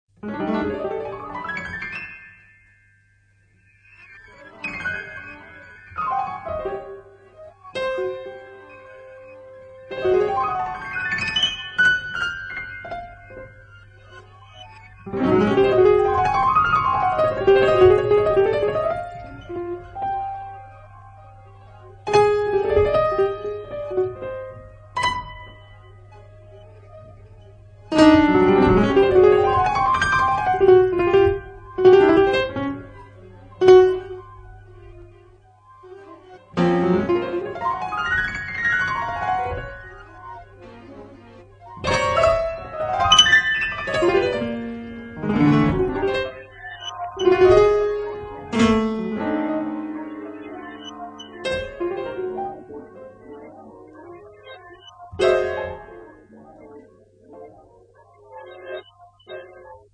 pianoforte, oggetti
live electronics
Un'esecuzione inconsueta per un organico atipico
L'intero album è stato registrato dal vivo